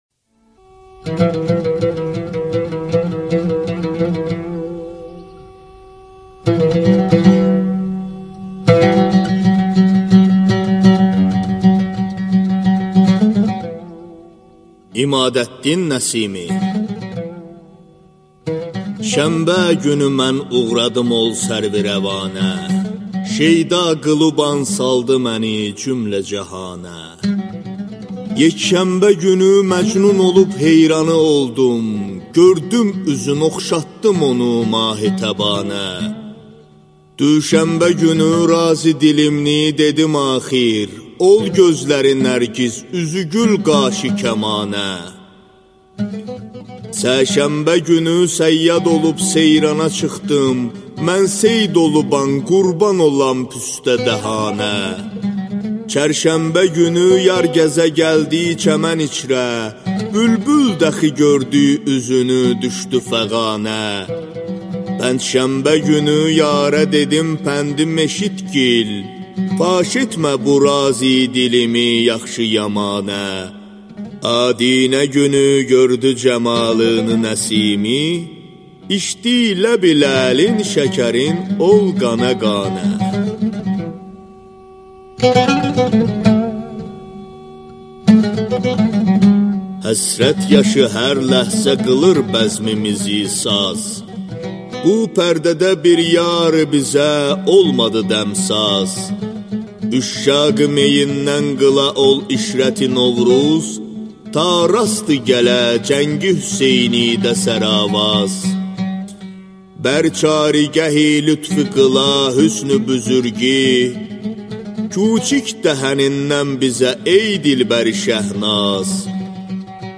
Аудиокнига İmadəddin Nəsiminin qəzəlləri | Библиотека аудиокниг